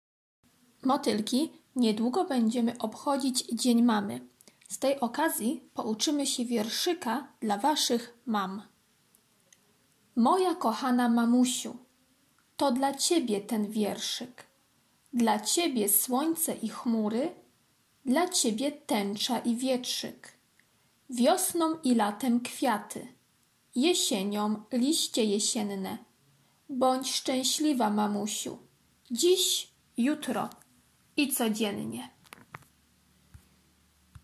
piątek - wierszyk dla mamy [1.00 MB] piątek - prezentacja "Barwa ochronna" [3.77 MB] piątek - ćw. dla chętnych - litera F, f [199.58 kB] piątek - ćw. dla chętnych - puzzle "Bocian" [165.93 kB] piątek - ćw. dla chętnych - kolorowanka [358.80 kB] ćw. dla chętnych- sylaby [315.50 kB]